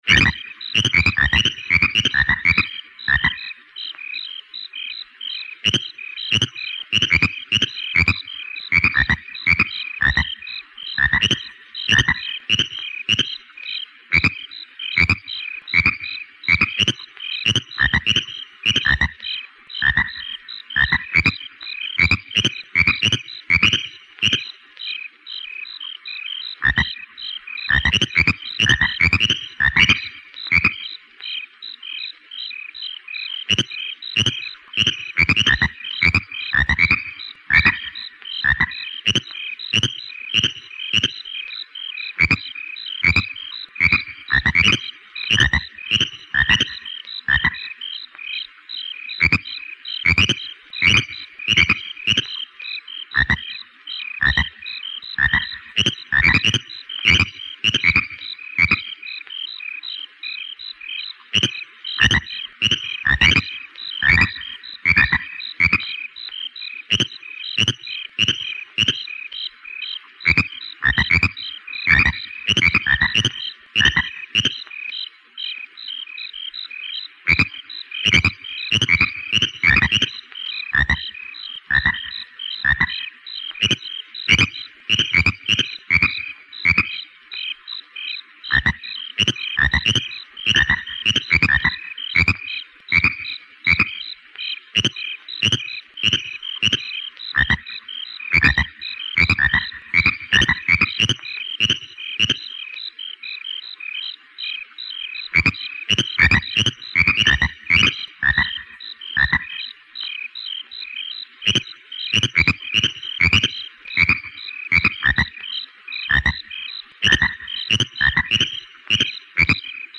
and omg aliens singing!!! :O